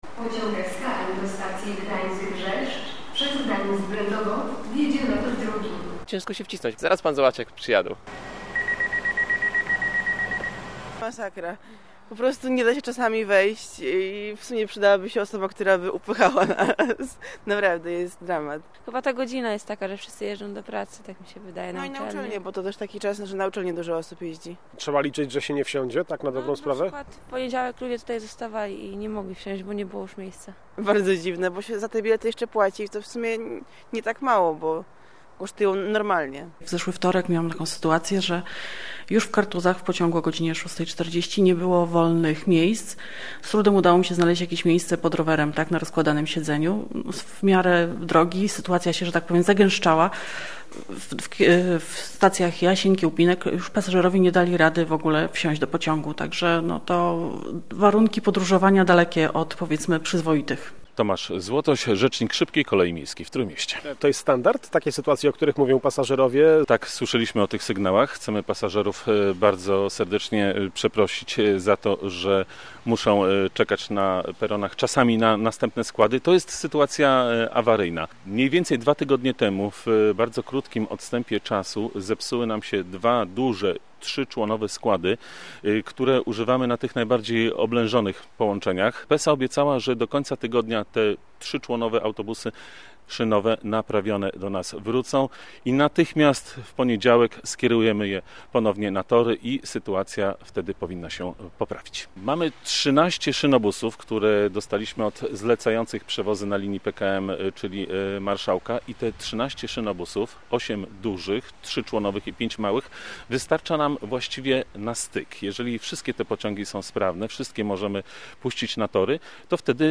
Ci, którzy nie zmieszczą się, muszą czekać na kolejny pociąg – mówią pasażerowie, z którymi rozmawialiśmy rano na przystanku w Jasieniu.